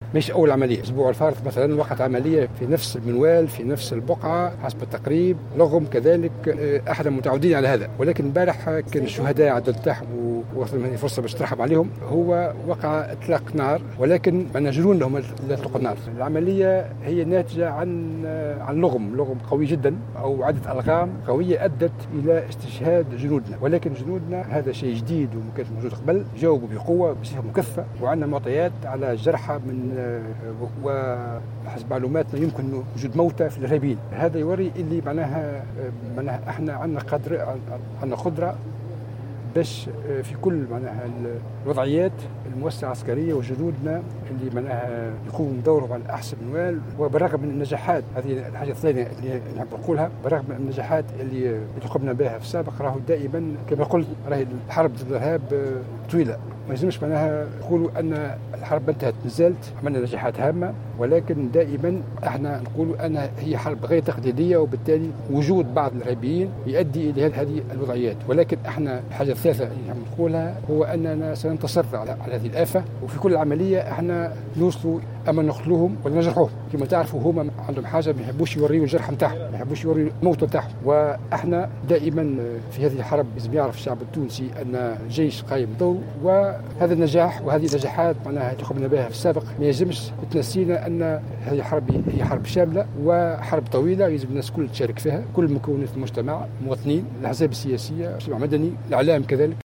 وأكد وزير الدفاع على هامش تصريحات في بنزرت صباح اليوم بمناسبة تسلم باخرة غوص ايطالية، نجاح عناصر الجيش الوطني في التصدي لهجوم الارهابيين المباغت الذي أسفر عن سقوط ثلاثة شهداء من الجيش الوطني.